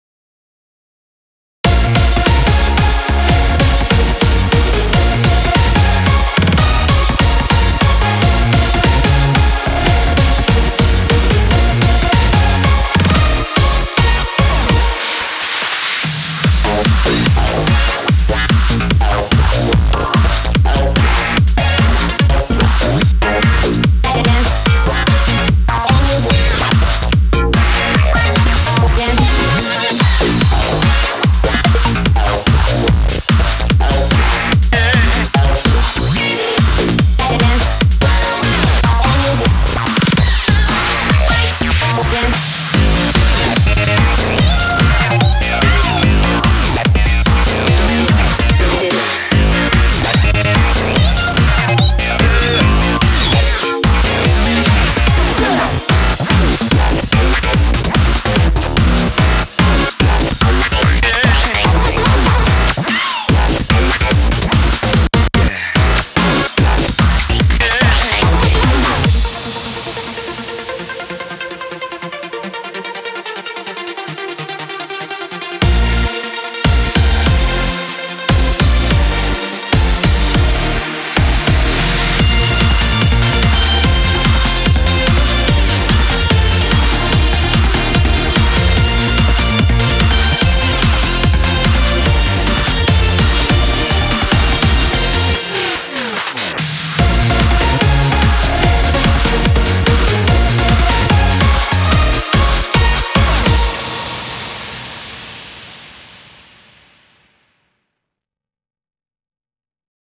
BPM146
Audio QualityPerfect (High Quality)